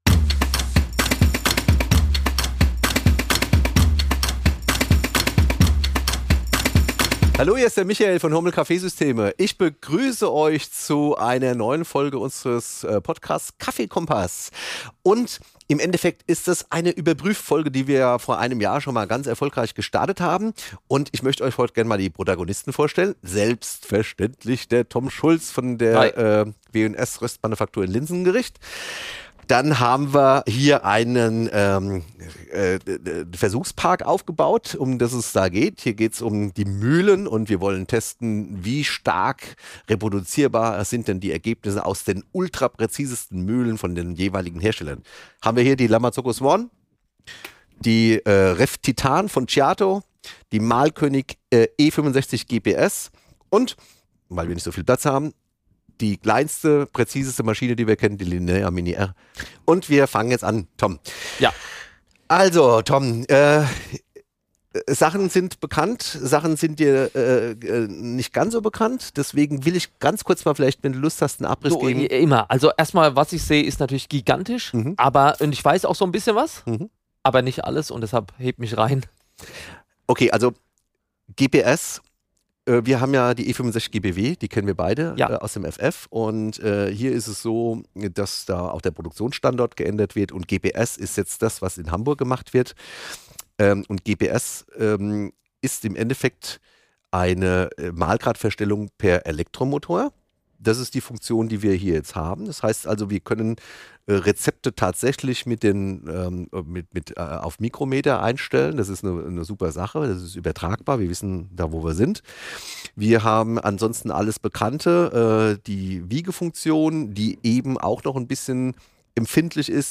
Live Test | Reproduzierbarkeit von 10 Shots mit verschiedenen Hochleistungsmühlen | KaffeeKOMPASS überprüft | Folge 78 ~ KaffeeKOMPASS Podcast